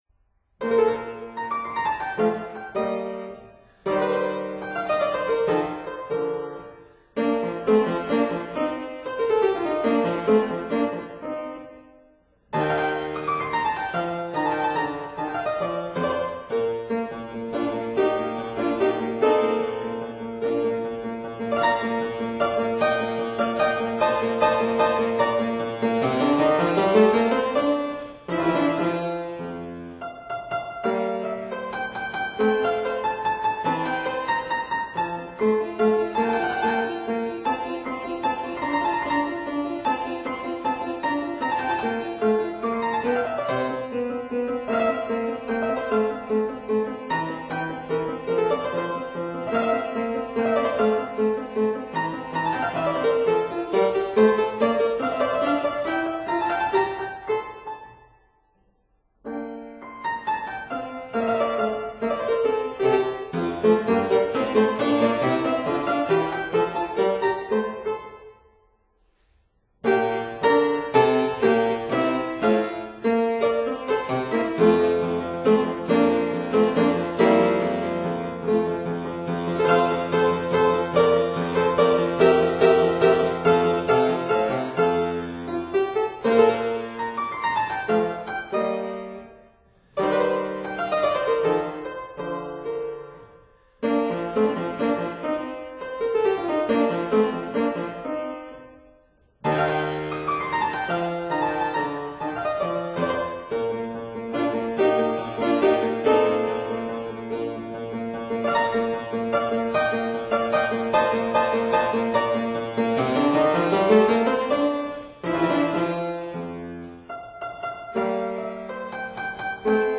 Fortepiano & Harpsichord
(Period Instruments)